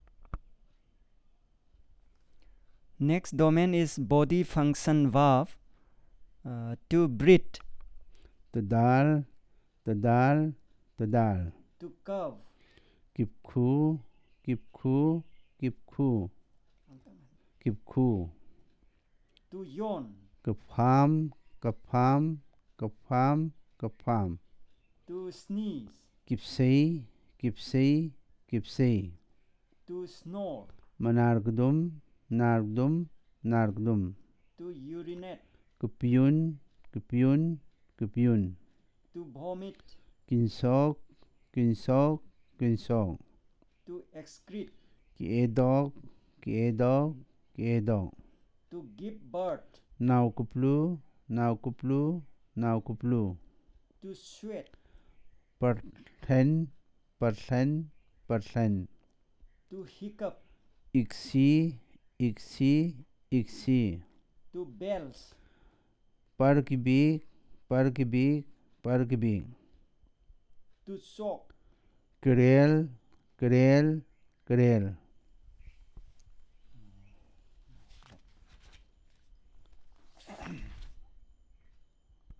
NotesThis is an elicitation and recording of a few body function verbs based on the SPPEL language documentation handbook.